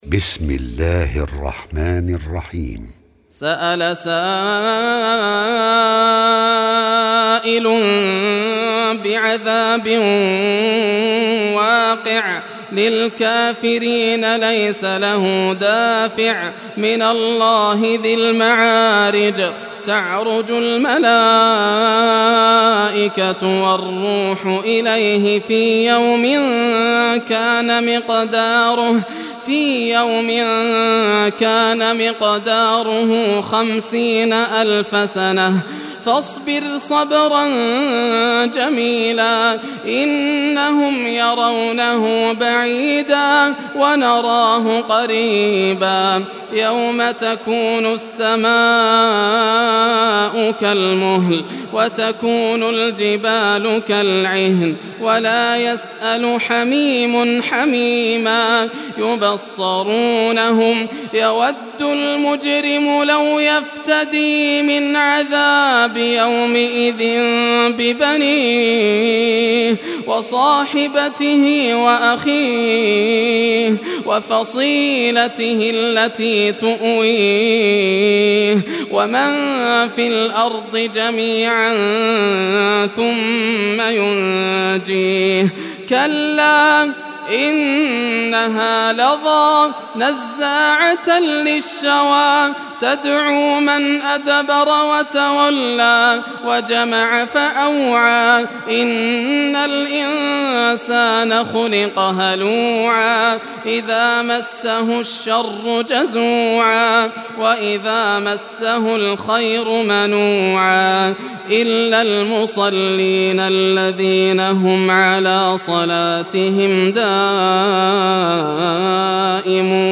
Surah Al-Maarij Recitation by Yasser al Dosari
Surah Al-Maarij, listen or play online mp3 tilawat / recitation in Arabic in the beautiful voice of Sheikh Yasser al Dosari.